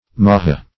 Maha \Ma"ha\, n. (Zool.)